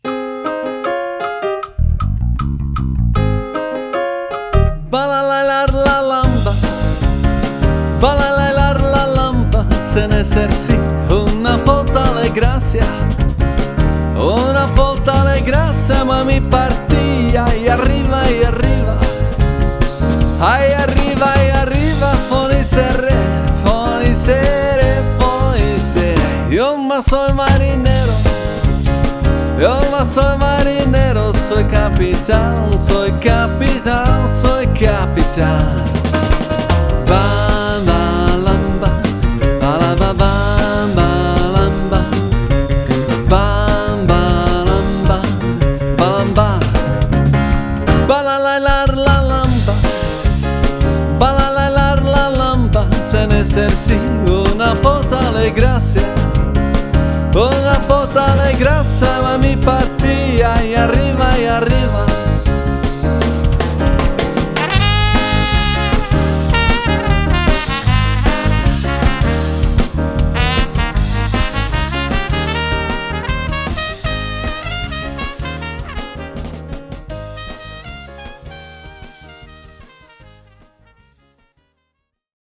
Romantické písně v duetu